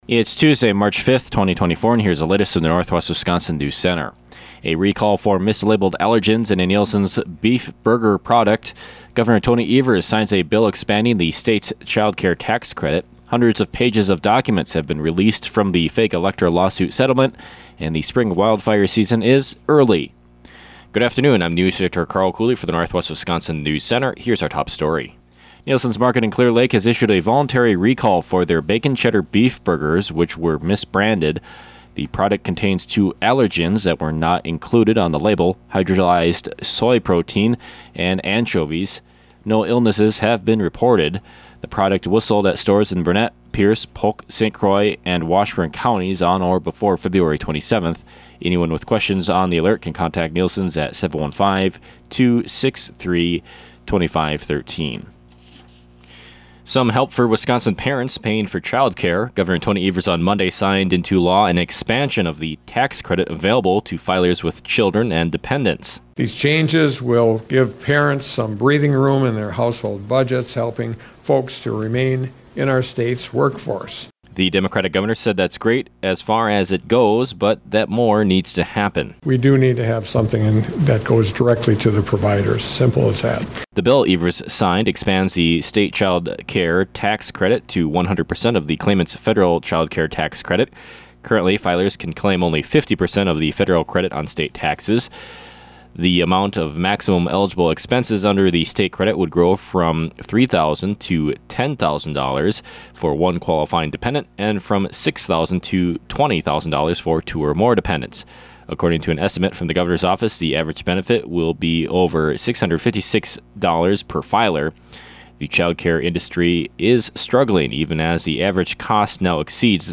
These stories and more on today’s local newscast.